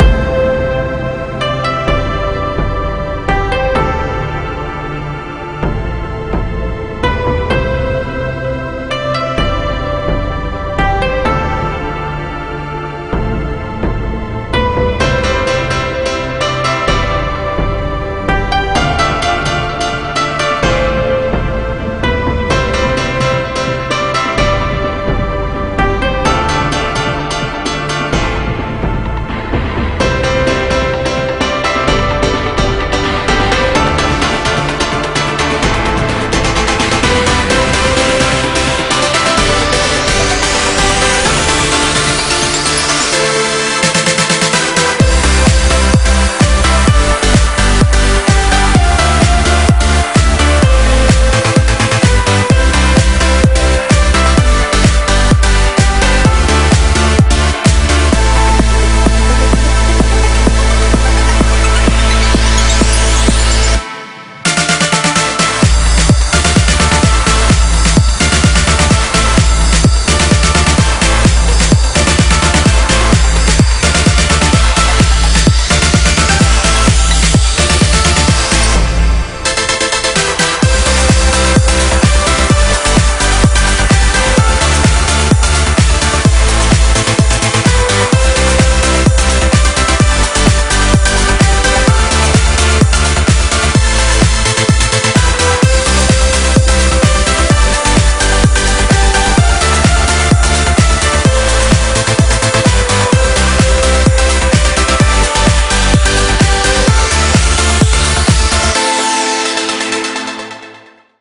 BPM64-128